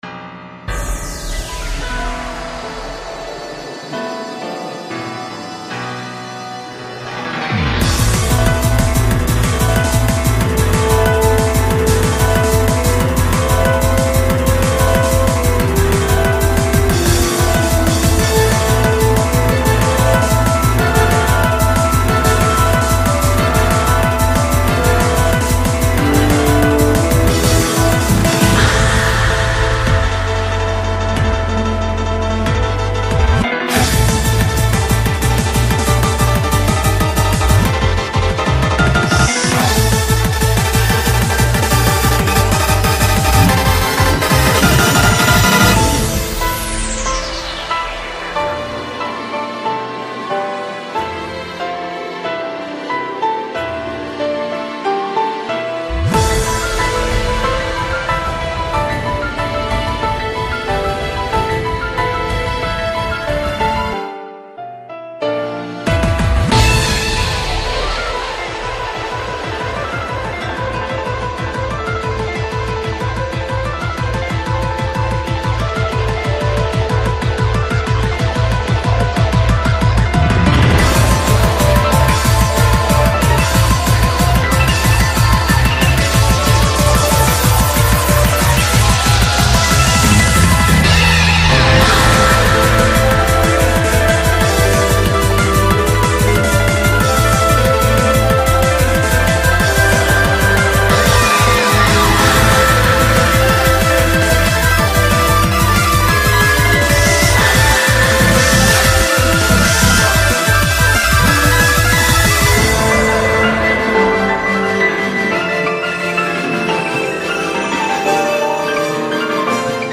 BPM92-185
Audio QualityPerfect (High Quality)
Genre: ESOTERIC SPEEDCORE